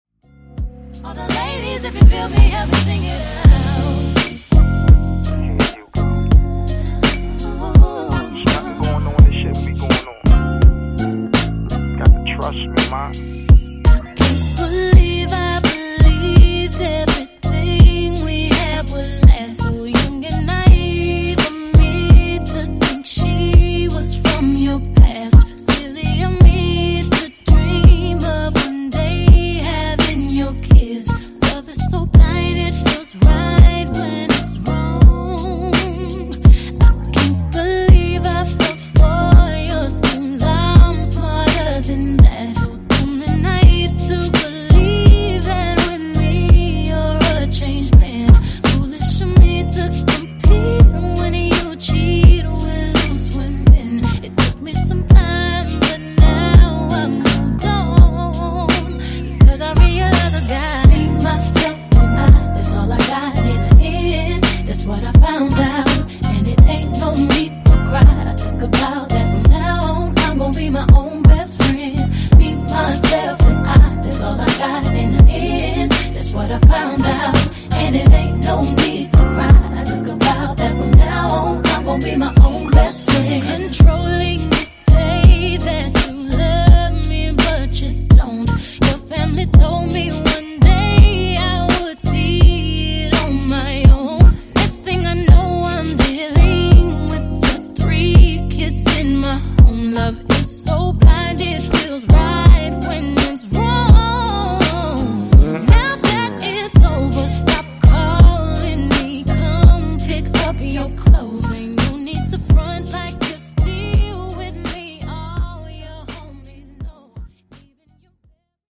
Genre: RE-DRUM
Clean BPM: 130 Time